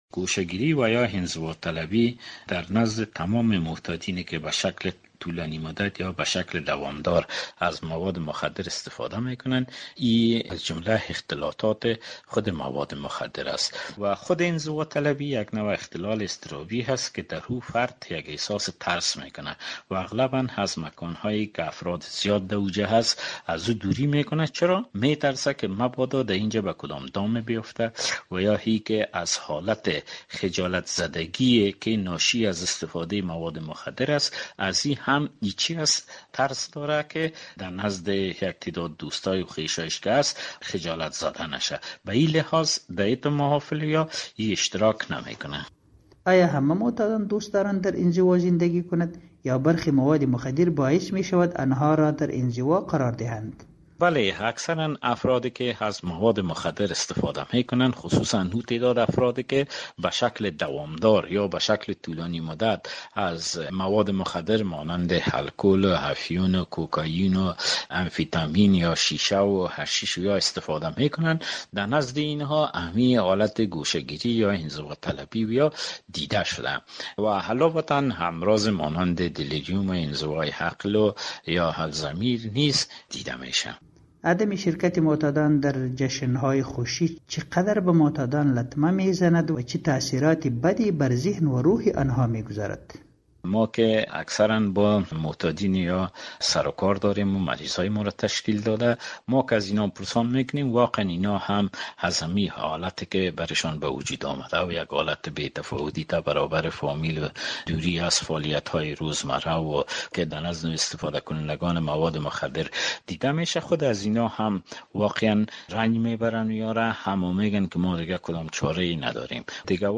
فرد معتاد به مواد مخدر٬ با گذشت زمان گوشه گیر شده و از خانواده، جامعه و اقاربش فاصله میگیرد و در گردهمایی ها و محافل خوشی اشتراک نمیکند. داکتران به این نظر اند که این کار سبب ایجاد مشکلات روانی به فرد معتاد و نگرانی اعضای خانواده اش میشود. همکار ما در مصاحبه